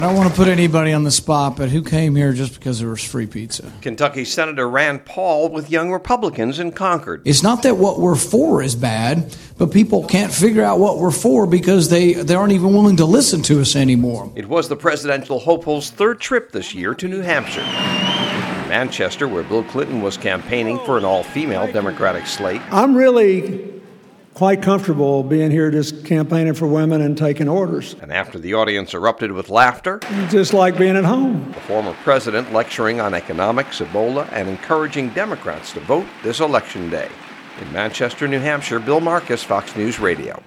New Hampshire Democrats Thursday were treated to an hour of former President Clinton.